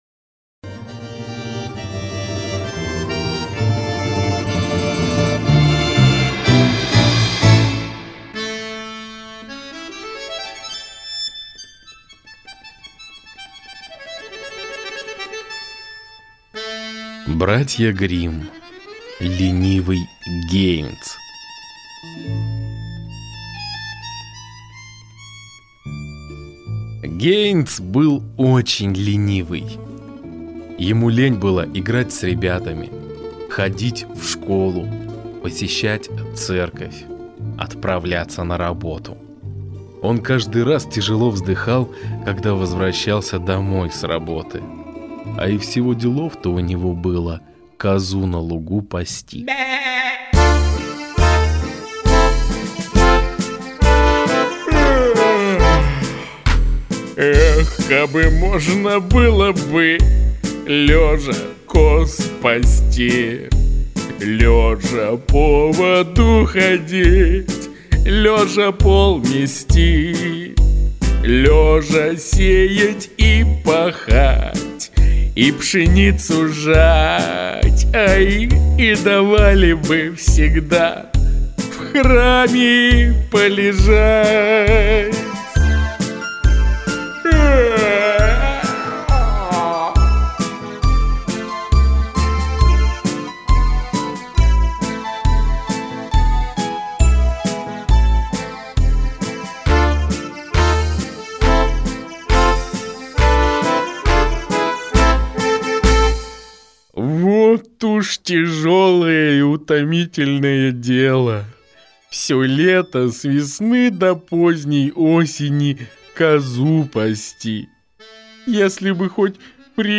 Аудио сказка «Ленивый Хайнц» братьев Гримм.